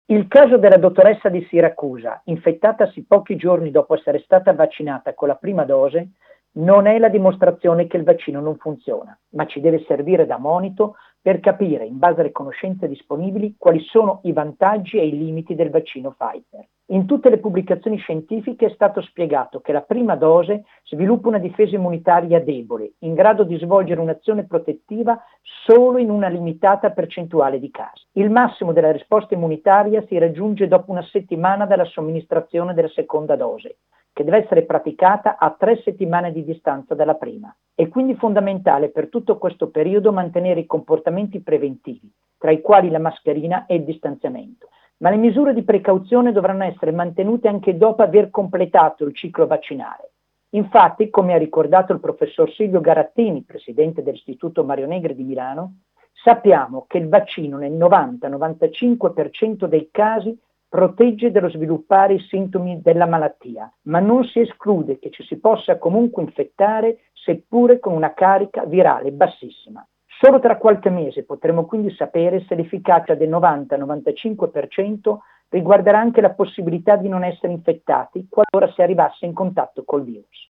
Oggi molte testate d’informazione hanno rilanciato come notizia la positività al Covid di una dottoressa dell’ospedale di Siracusa, tra le prime vaccinate in Sicilia. Vittorio Agnoletto, medico e autore della nostra trasmissione 37e2: